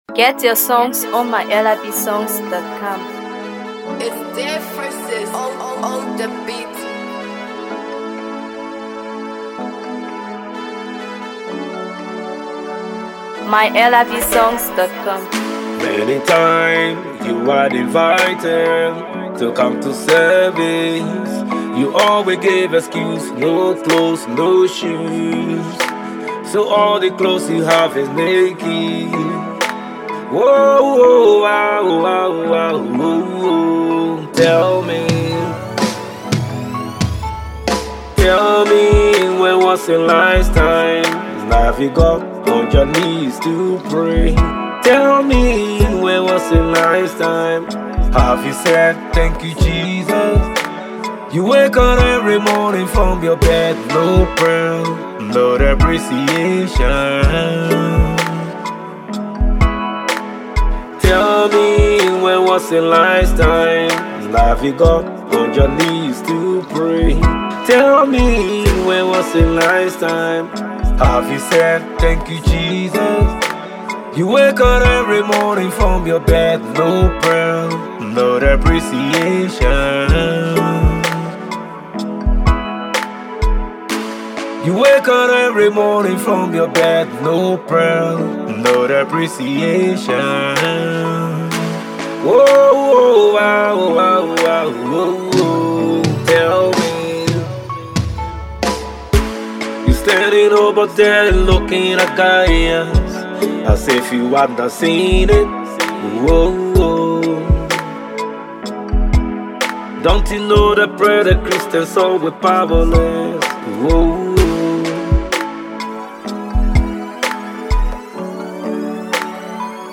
Gospel
Amazing Gospel Banger